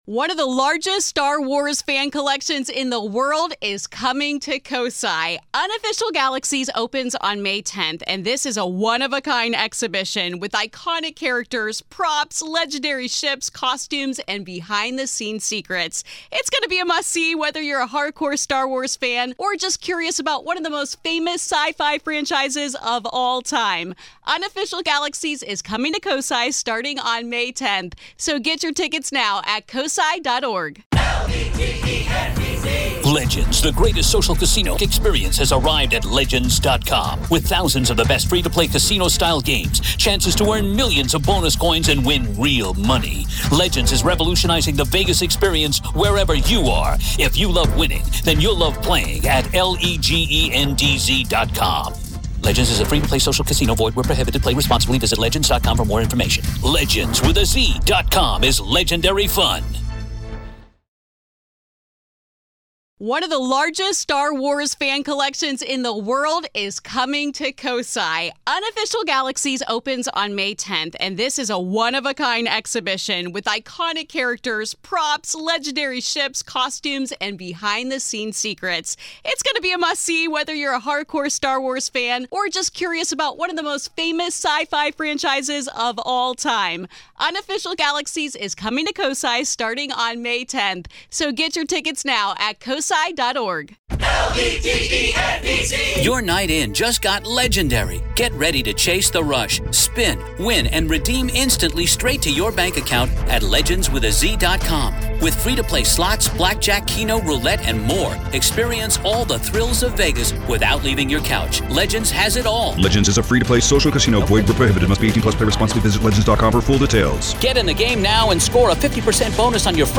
Today on The Grave Talks , in Part One of our conversation,